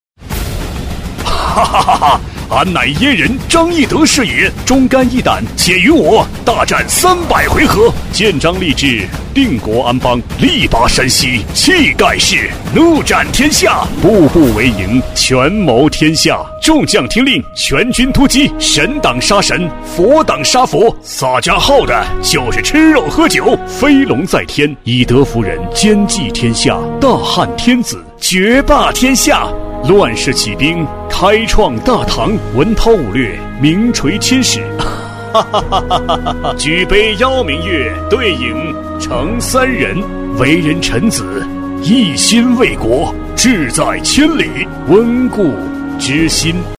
男390-游戏CG【张翼德等多角色 】
男390-年轻大气 大气浑厚
男390-游戏CG【张翼德等多角色 】.mp3